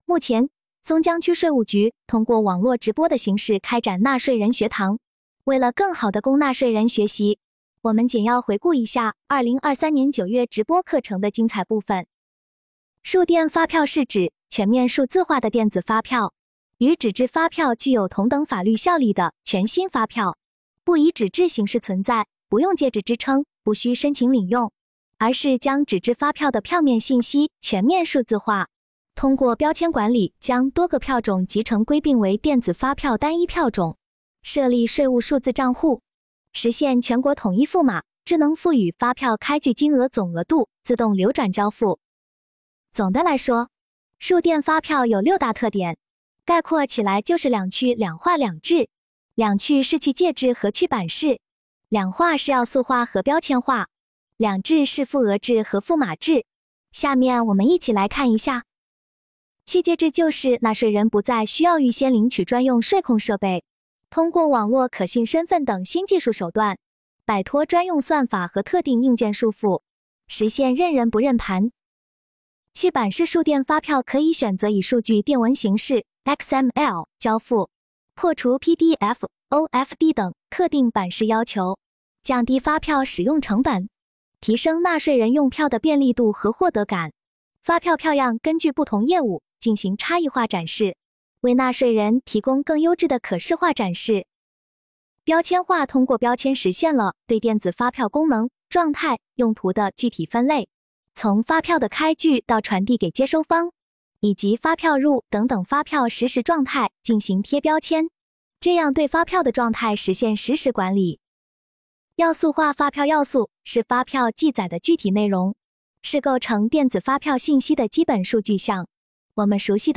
直播课程一
根据市局对数字化电子发票开票推广的工作部署，区局逐步推进数字化电子发票开票工作，为尽快让区内企业全面掌握数字化电子发票开票要点，纳税人学堂在9月分批次开展了“数字化电子发票开票培训会”直播。